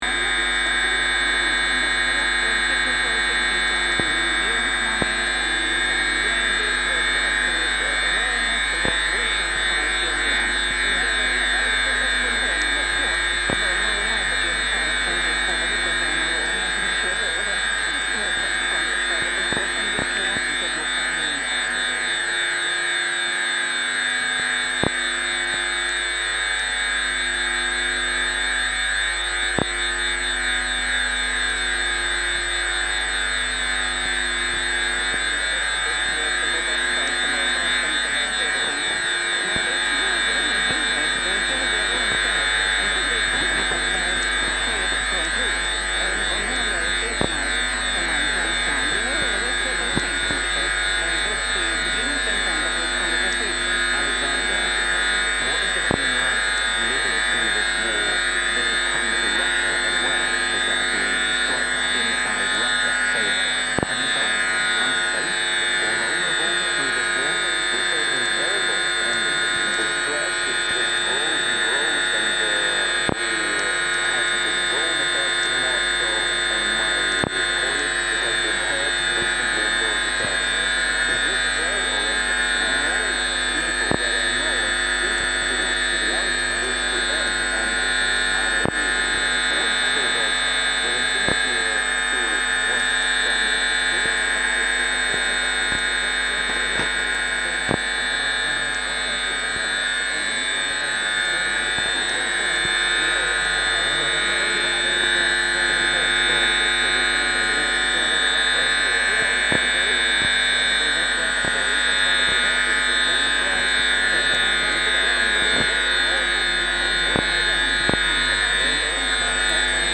The hum of Yanchep substation
This recording is taken with an Open Wave-Receiver, a custom made foxhole radio modded from a Shortwave Collective design.
The aerial was strung up to the barbed wire fence around Yanchep Substation; the electromagnetic pulse capturing an invisible soundscape, this community’s lifeline to the power grid.